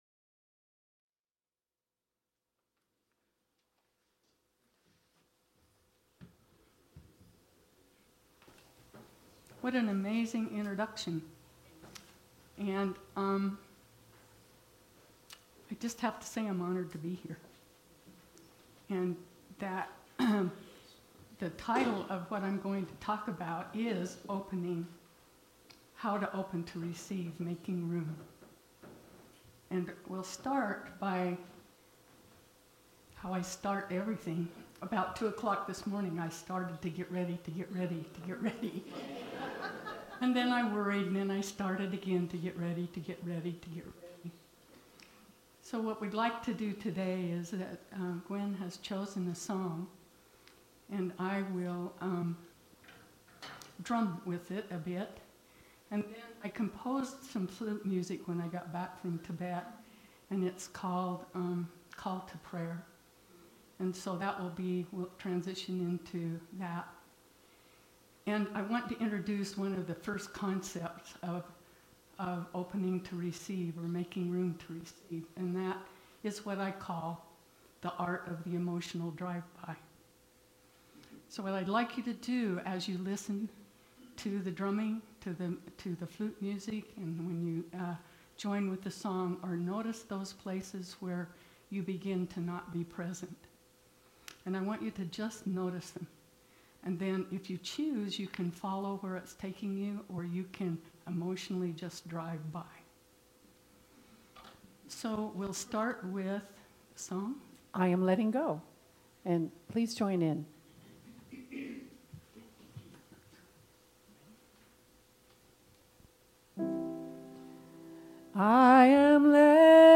The audio recording (below the video clip) is an abbreviation of the service. It includes the Meditation and Message.